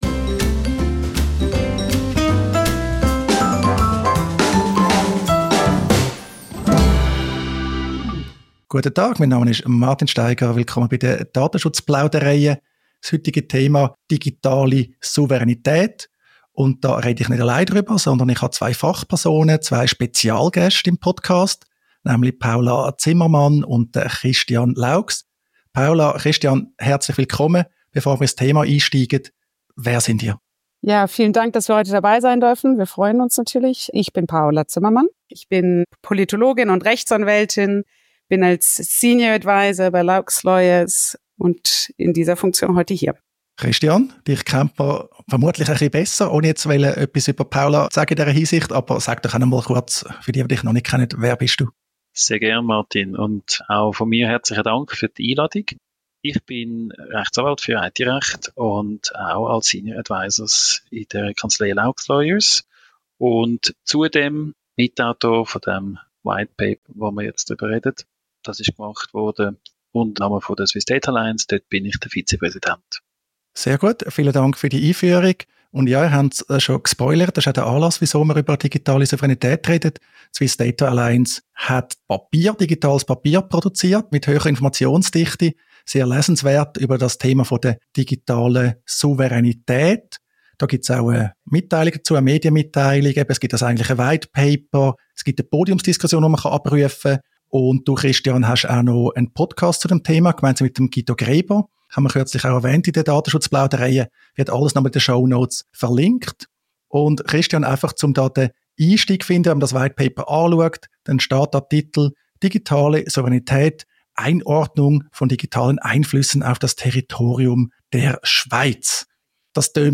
ein ausführliches Gespräch über digitale Souveränität. Anlass ist ein neues Whitepaper der Swiss Data Alliance – und natürlich der 1. August.